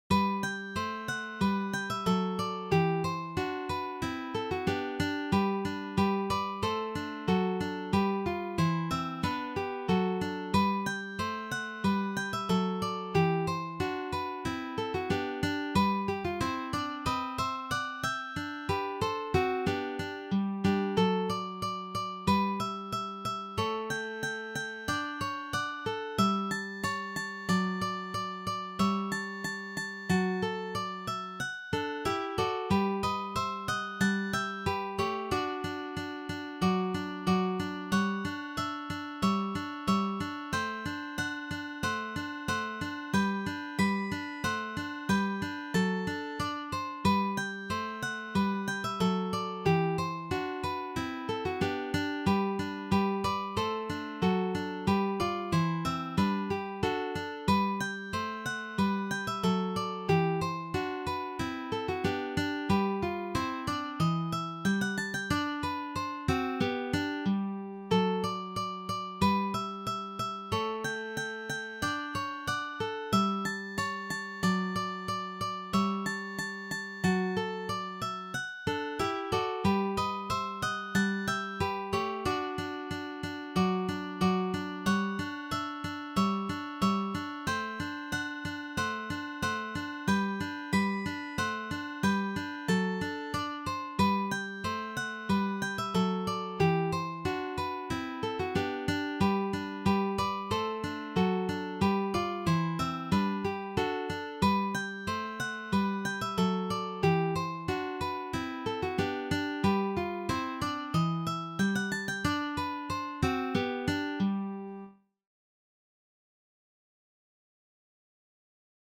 for three guitars
This is from the Classical period.